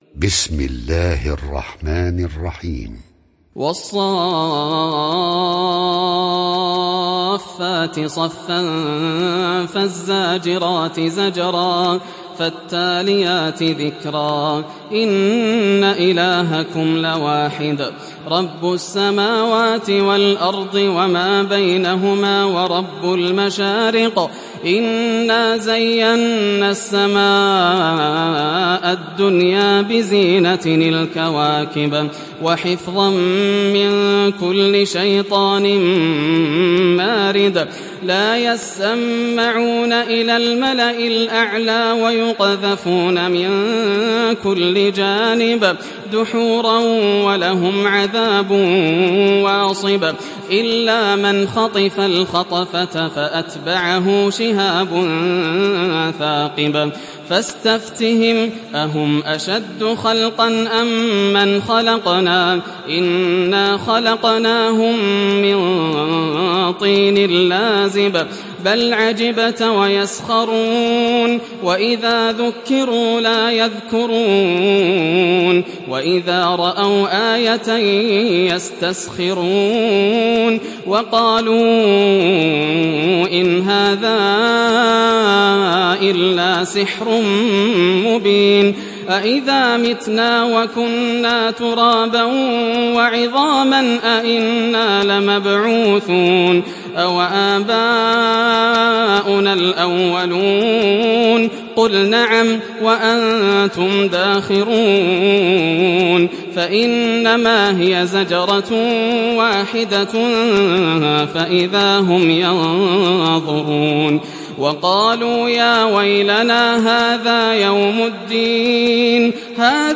Saffet Suresi İndir mp3 Yasser Al Dosari Riwayat Hafs an Asim, Kurani indirin ve mp3 tam doğrudan bağlantılar dinle